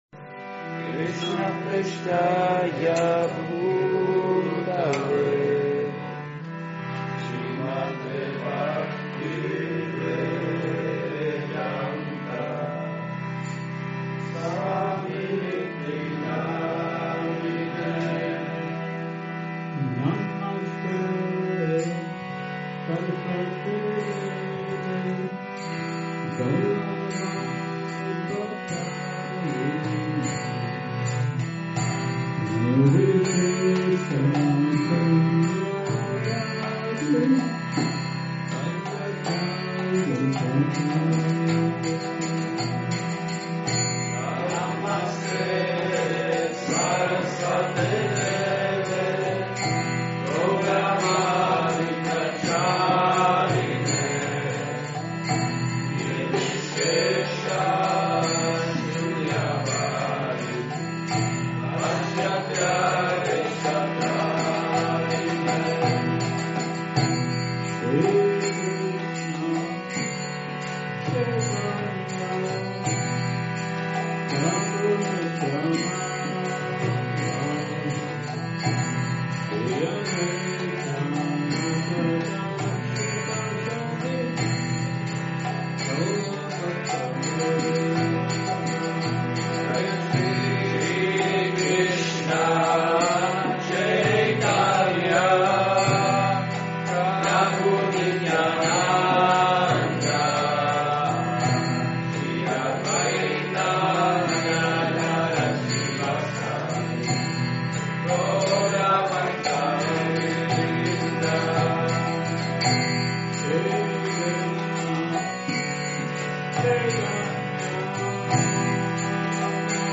Přednáška BG-9.1 – restaurace Góvinda